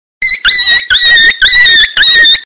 Puppy Dog Sound Button - Free Download & Play